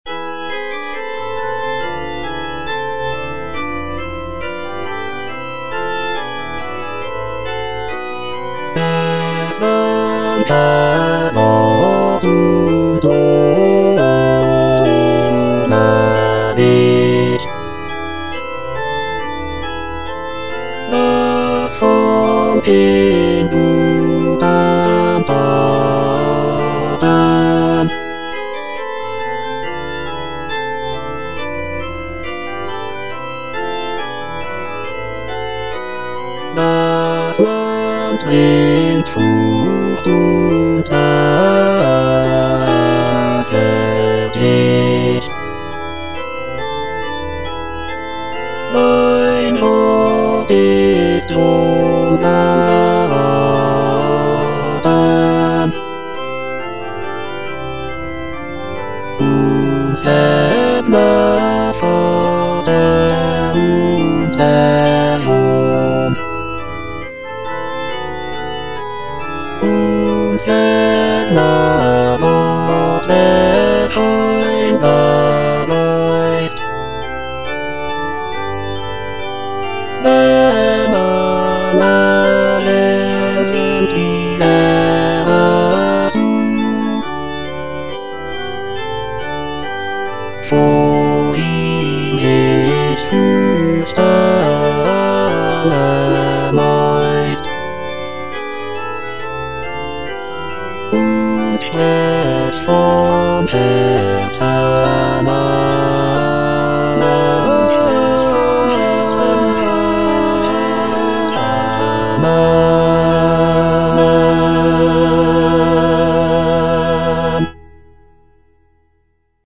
The work features intricate choral writing, beautiful melodies, and rich orchestration, showcasing Bach's mastery of baroque music composition."